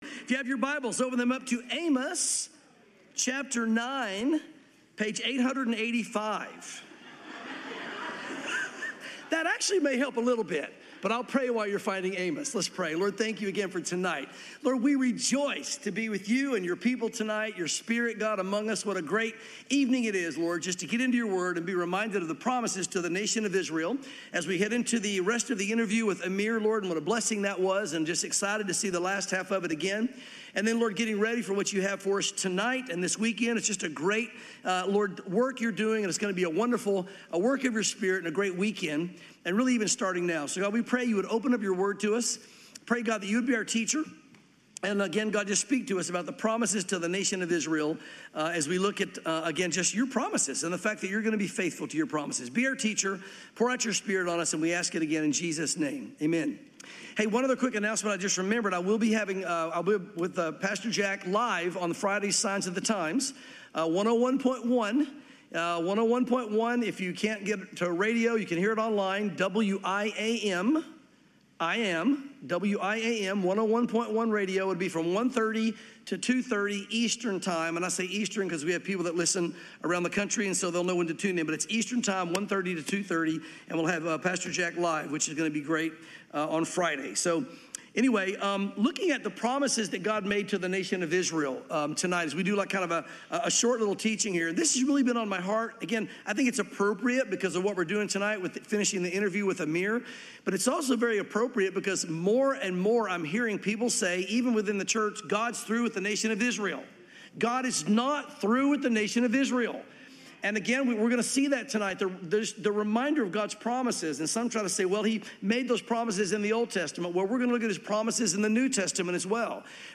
Calvary Chapel Knoxville